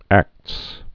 (ăkts)